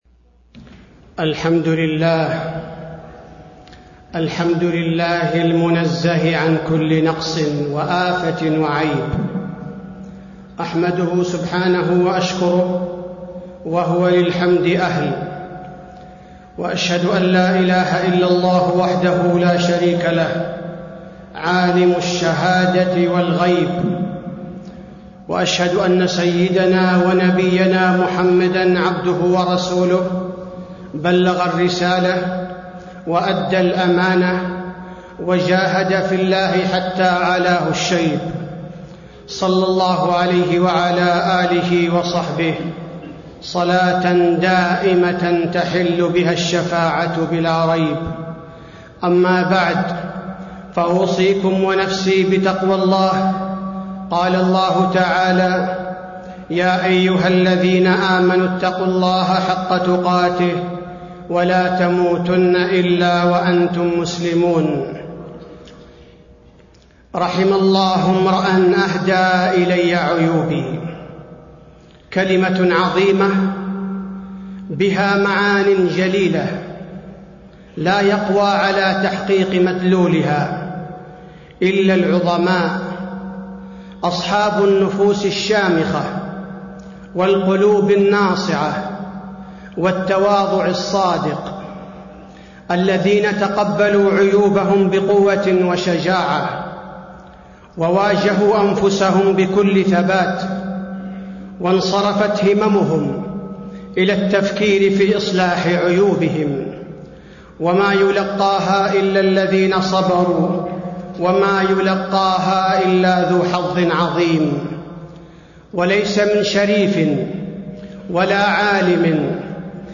تاريخ النشر ١٥ صفر ١٤٣٤ هـ المكان: المسجد النبوي الشيخ: فضيلة الشيخ عبدالباري الثبيتي فضيلة الشيخ عبدالباري الثبيتي المنهج الرشيد في إصلاح العيوب